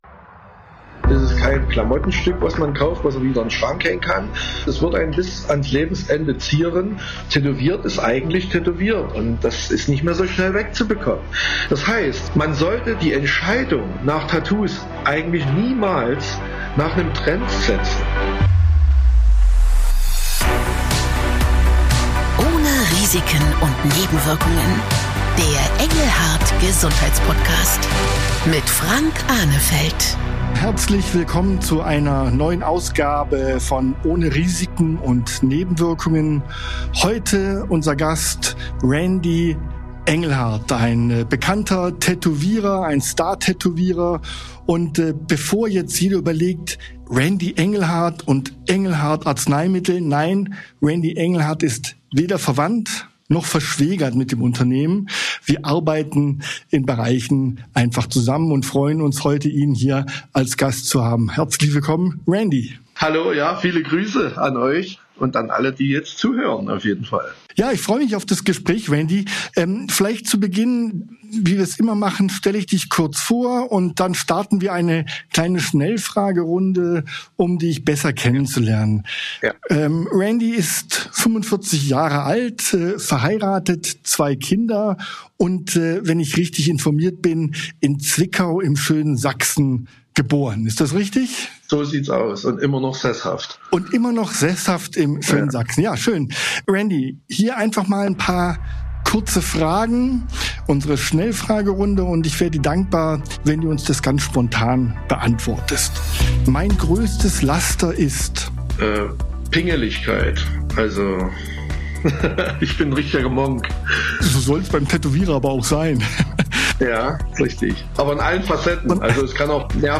Randy Engelhard liefert einen sehr persönlichen Einblick in sein spannendes Leben.